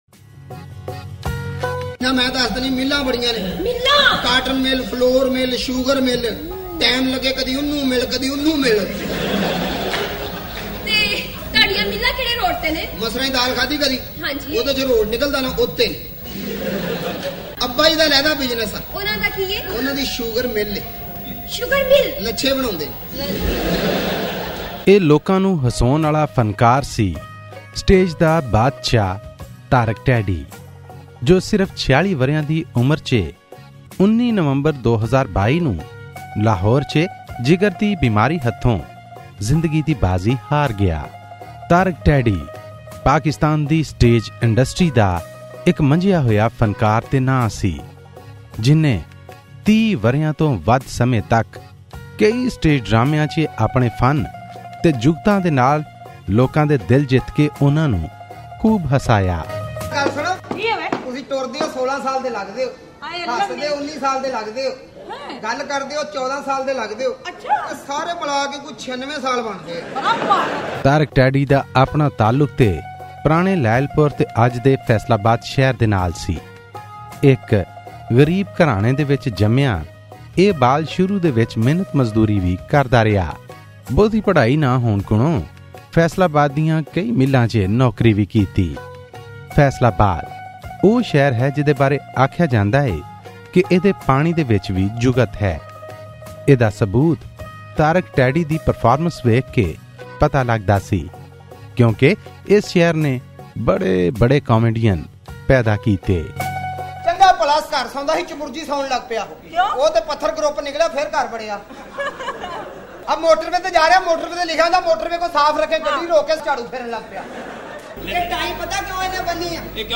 ਦਹਾਕਿਆਂ ਬੱਧੀ ਲੋਕਾਂ ਦਾ ਮਨ ਪ੍ਰਚਾਉਣ ਵਾਲੇ ਪਾਕਿਸਤਾਨੀ ਡਰਾਮਾ ਕਲਾਕਾਰ ਤਾਰਿਕ ਟੈਡੀ ਬਾਰੇ ਵਿਸ਼ੇਸ਼ ਰਿਪੋਰਟ